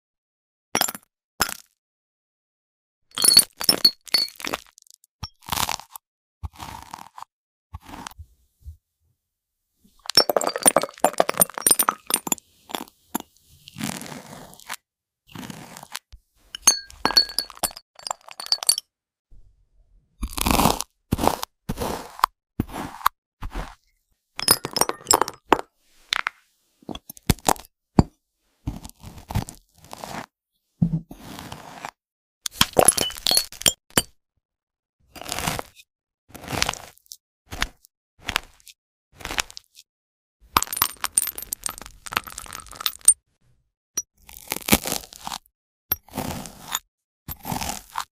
🍓Immersive glass jam with toast🍞, sound effects free download
ASMR decompression and sleep aid! Glass fruit turns into jam, and the crunchy sound of it on toast is a must-have for stress relief and sleep!